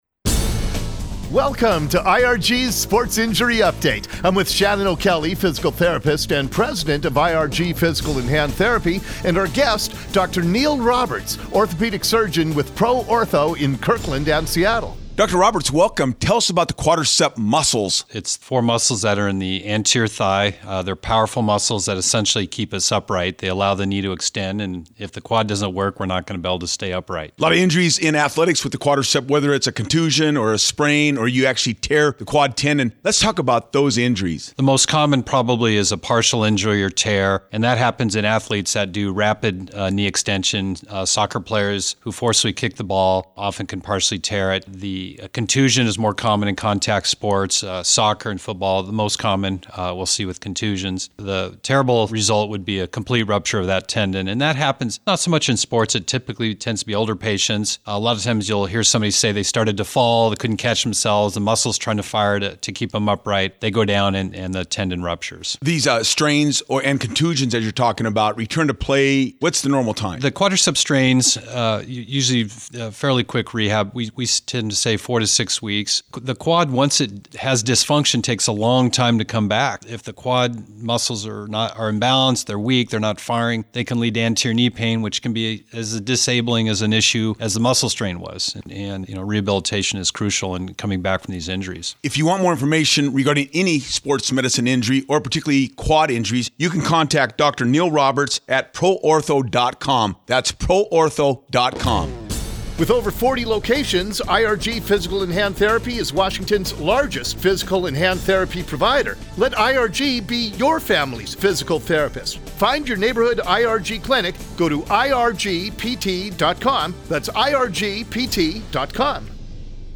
IRG Sports Injury Update – Quad Tendon Injuries Radio Segment: